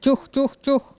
Cri pour appeler les cochons ( prononcer le cri )